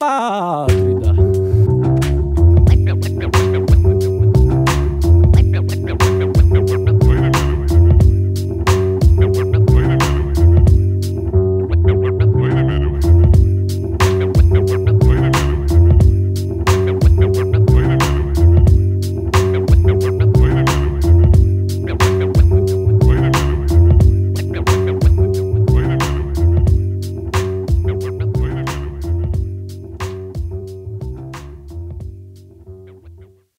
Rap Instrumentals